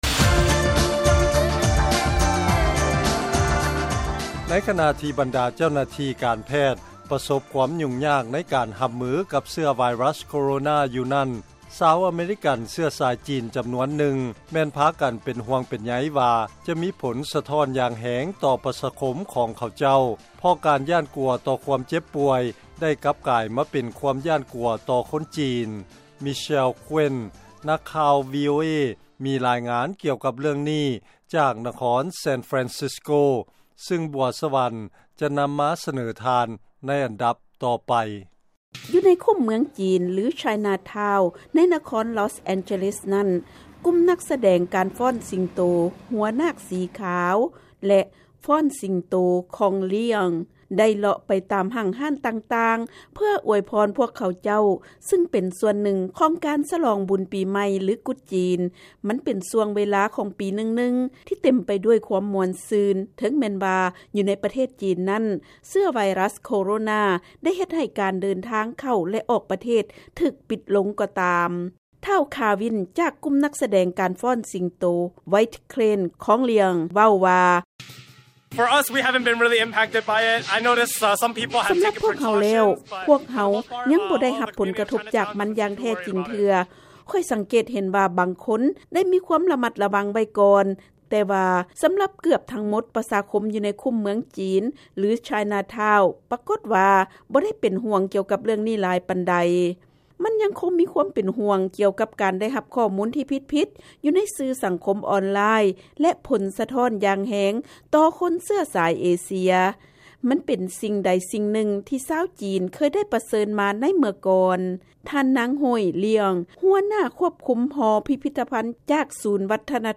ເຊີນຟັງລາຍງານກ່ຽວກັບຄວາມເປັນຫ່ວງຂອງຄົນອາເມຣິກັນເຊື້ອສາຍຈີນ ເມື່ອເຊື້ອໄວຣັສໂຄໂຣນາລະບາດຫລາຍຂຶ້ນ